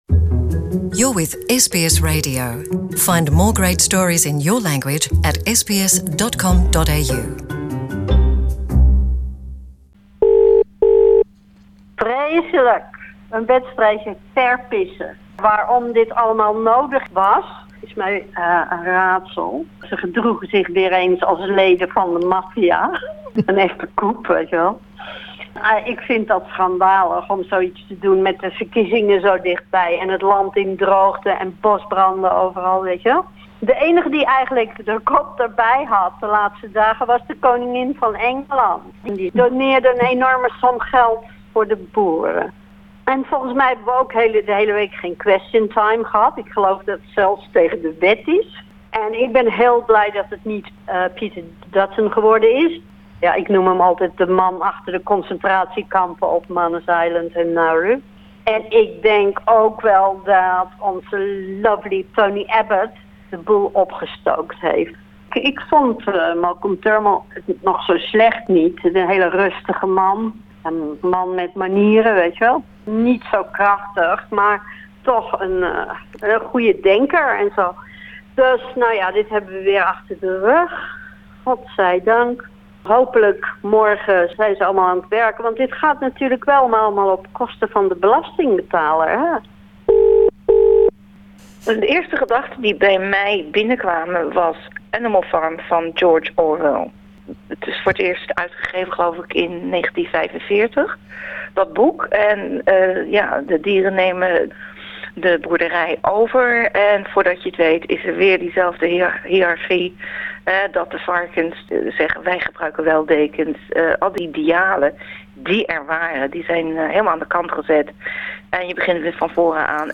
A quick ring-a-round resulted in a fair few different and sometimes blunt opinions about the Westminster system, Scott Morrison, 'polderen' and the swing to the right.